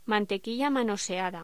Locución: Mantequilla manoseada
voz
Sonidos: Hostelería